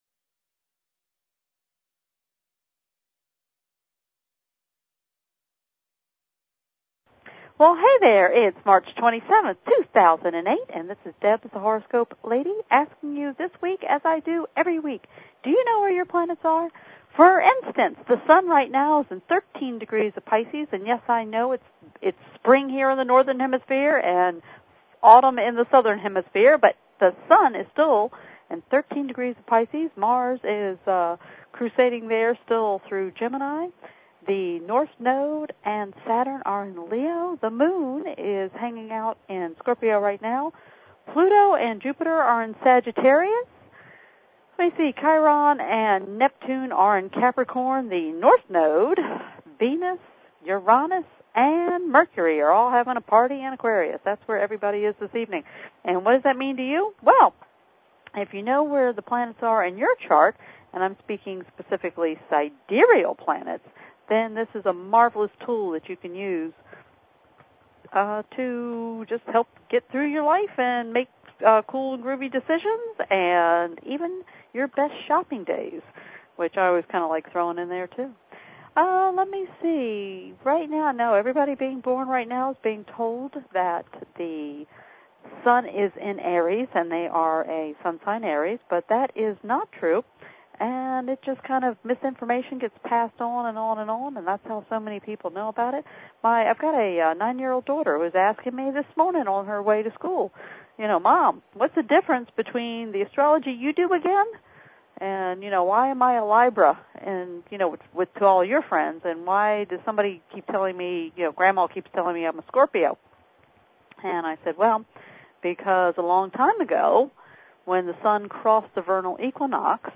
Talk Show
Her guests include other leading sidereal astrologers and the occasional celebrity who has found sidereal astrology useful.